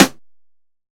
DillaTubSnare2.wav